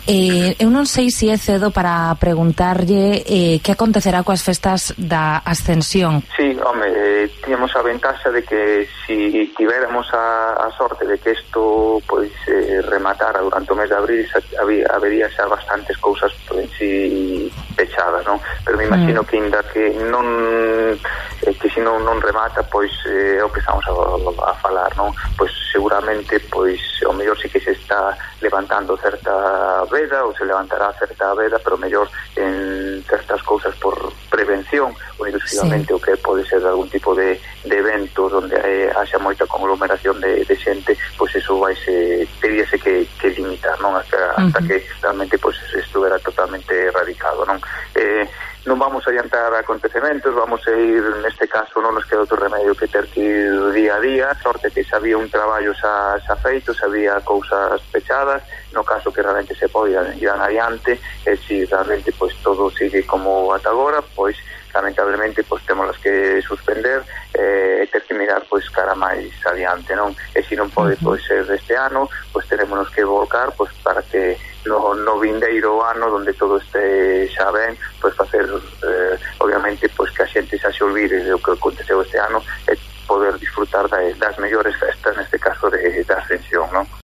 El concejal de Fiestas de Compostela, Gonzalo Muíños, nos explicaba en los micrófonos de COPE Santiago que "tenemos la ventaja de que si esto terminase durante el mes de abril, ya habría muchas cosas cerradas para las fiestas, pero si no acaba, aunque se levante la veda, por prevención, habría que limitar los eventos con gente".